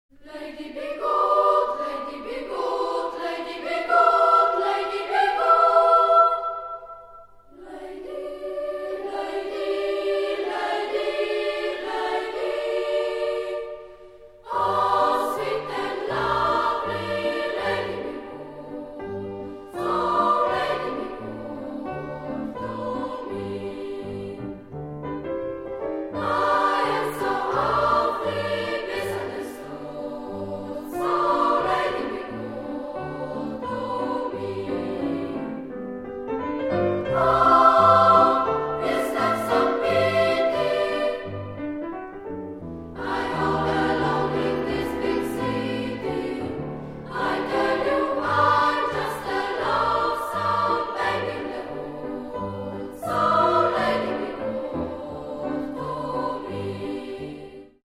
wykonuje chór dziewczêcy